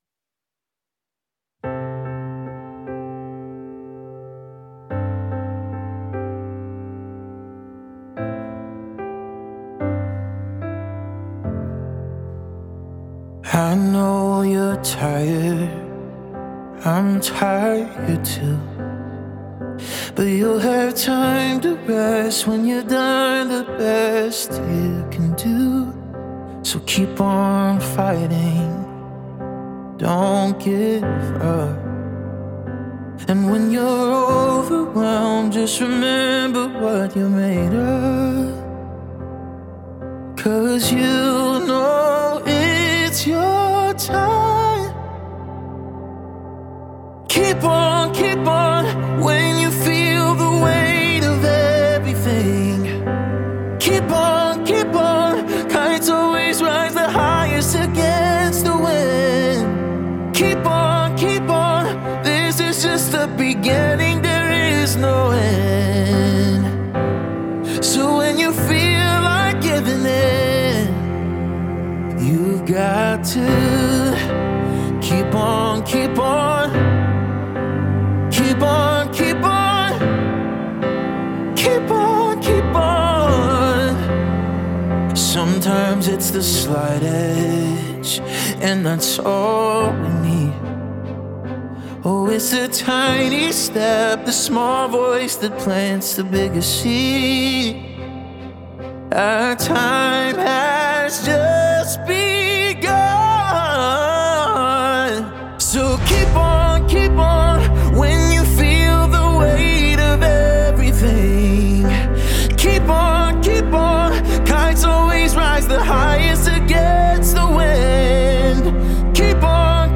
Oct 1, 2022 | Pop, Singer-Songwriter, Songs
Ambient, Female vocal, Harmonies, Singer-Song Writer